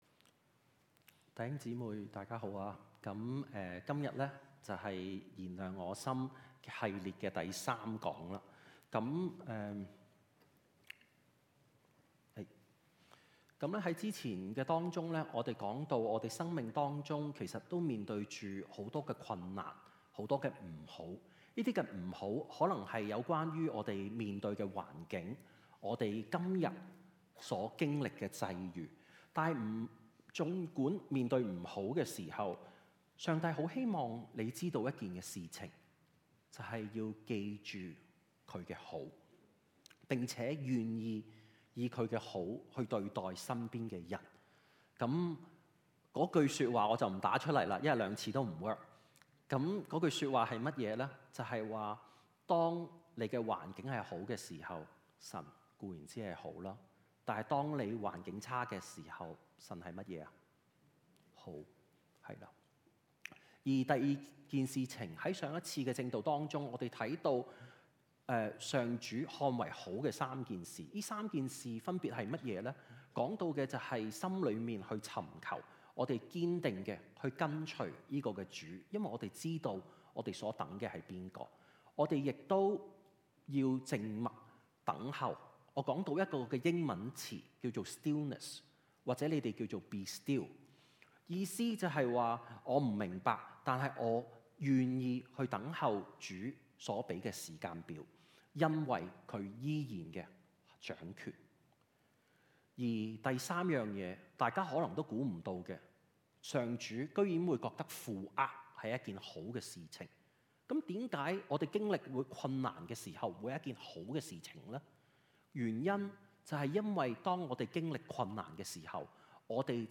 證道 | South Gate Alliance | Cantonese (粵語)
由於錄影中途中斷, 請大家點擊Audio 檔聽完整證道錄音。